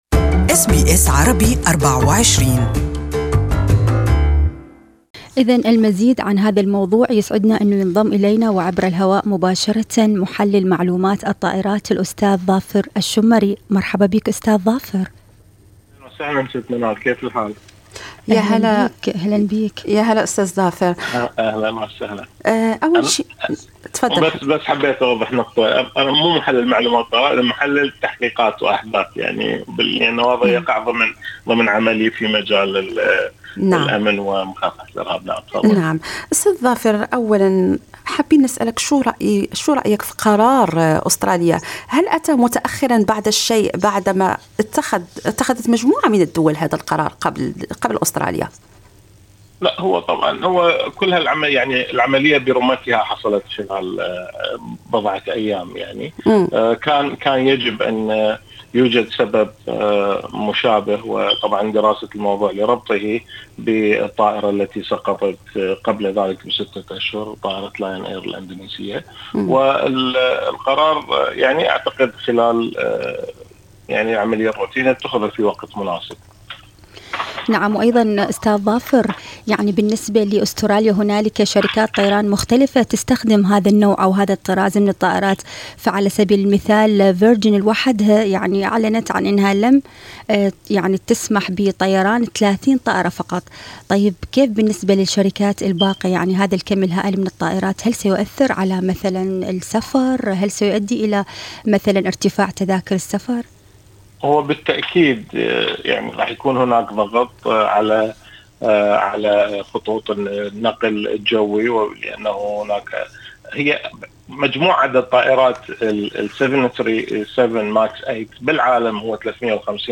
This interview is only available in Arabic